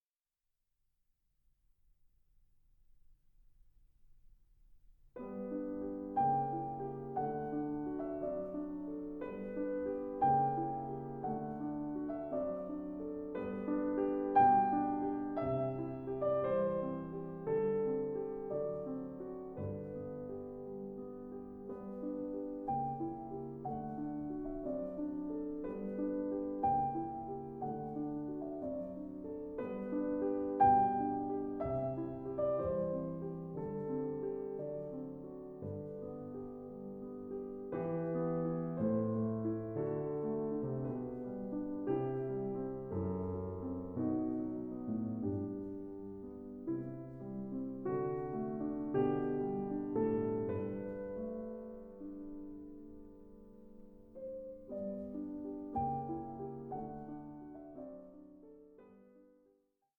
Andantino grazioso 5:07